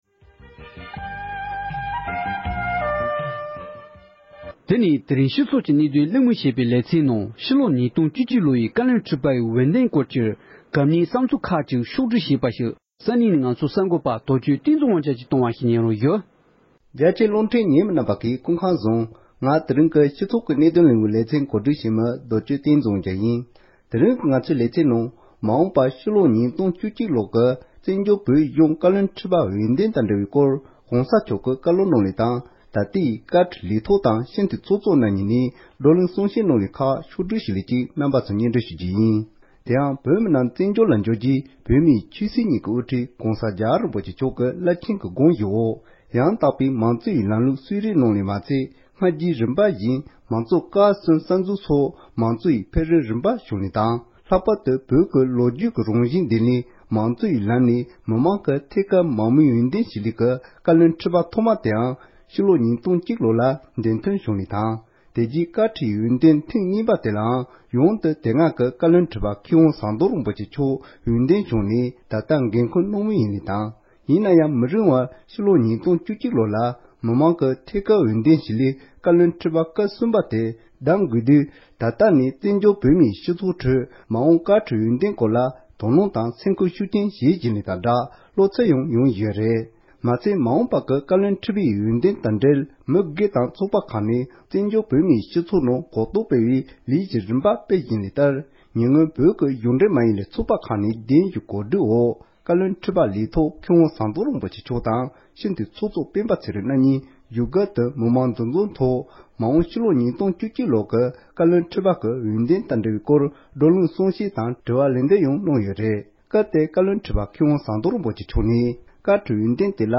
མ་འོངས་པར་ཉིས་སྟོང་བཅུ་གཅིག་ལོའི་བཀའ་བློན་ཁྲི་པའི་འོས་འདེམས་དང་འབྲེལ་བའི་བཀའ་སློབ་དང་བགྲོ་གླེང་གསུང་བཤད་ཁག་གི་སྙིང་བསྡུས།